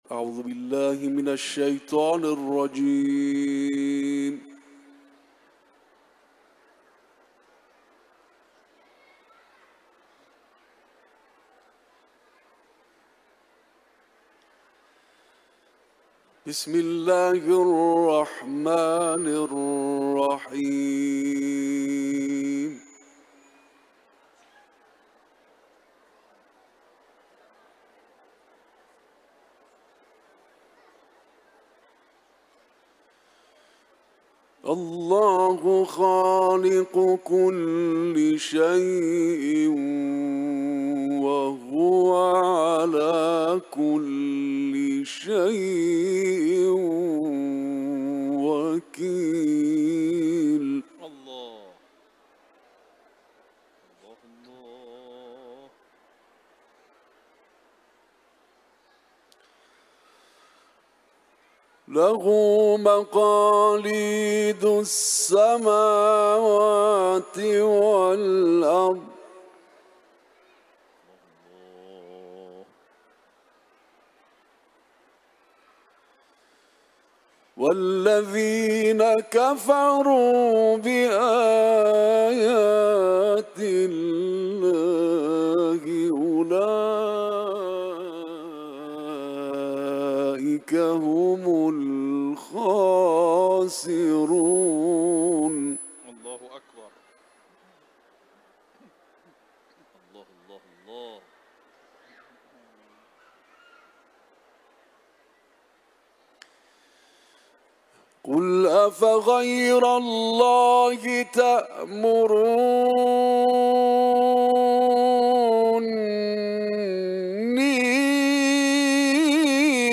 Etiketler: İranlı kâri ، Zümer suresi ، Kuran tilaveti